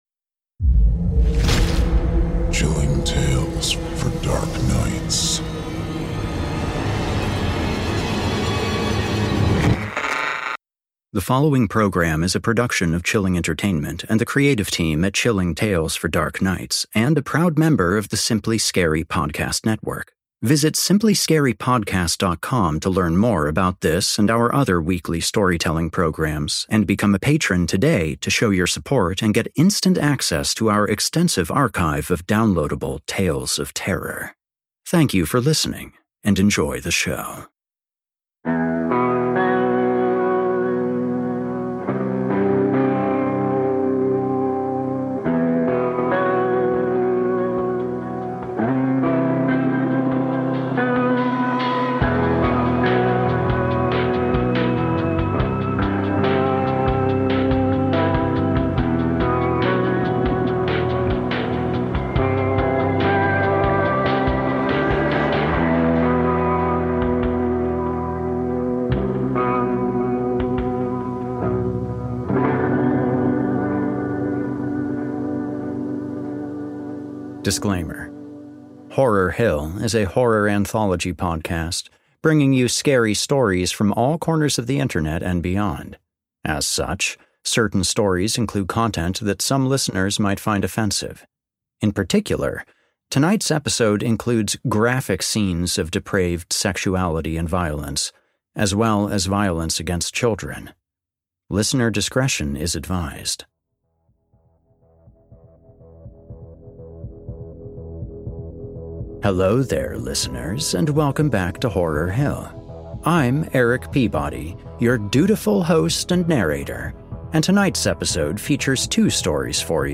Horror Hill — A Horror Fiction Anthology and Scary Stories Series Podcast / S13E10 - "Kith and Kin" - Horror Hill